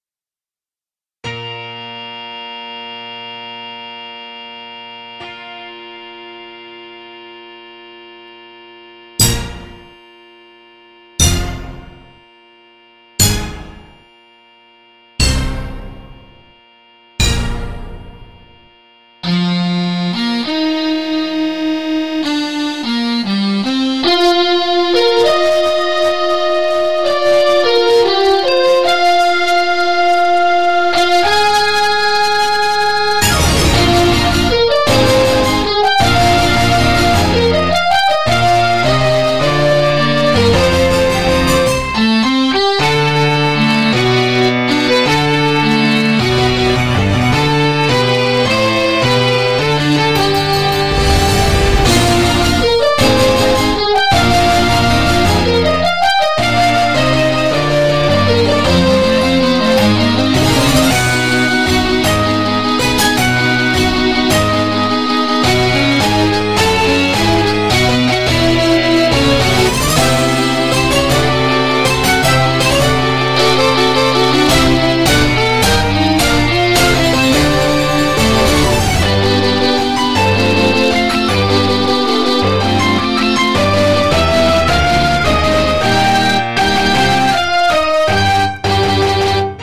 NES music covers
guitars
cover